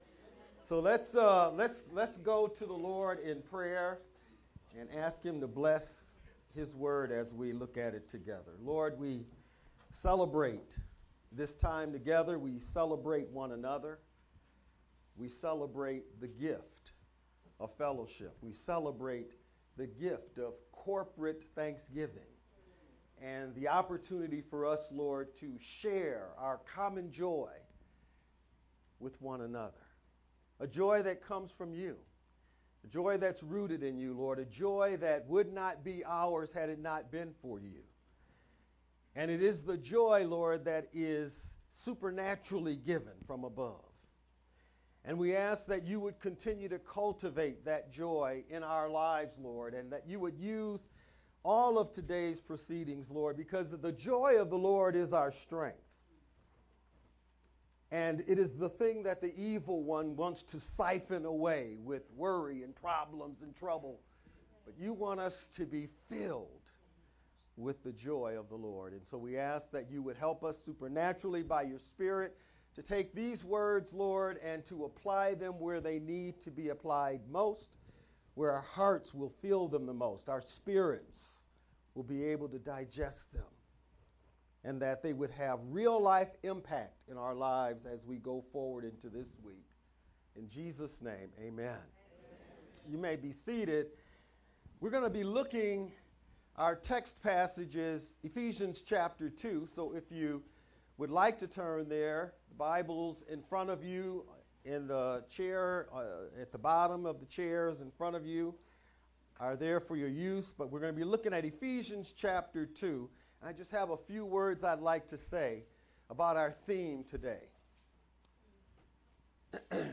Feb-22nd-VBCC-Sermon-only-CD.mp3